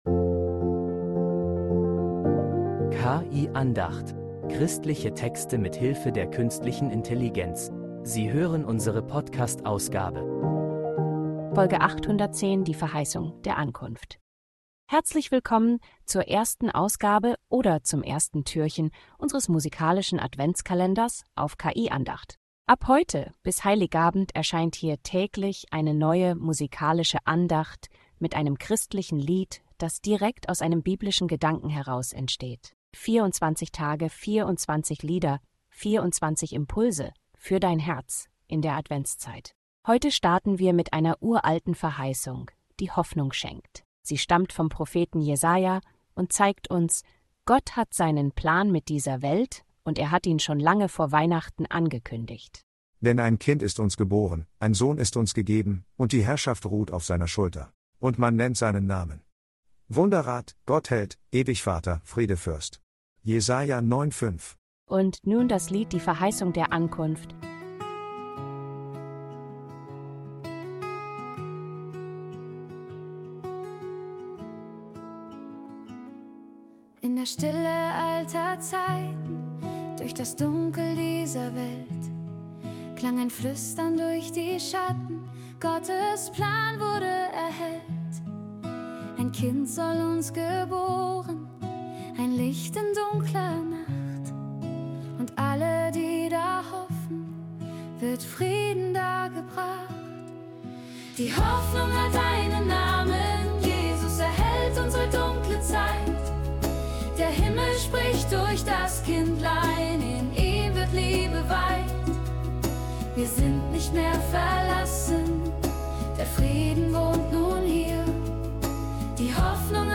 Christliche Texte mit Hilfe der Künstlichen Intelligenz
Musik macht daraus ein hoffnungsvolles Lied, das den Advent in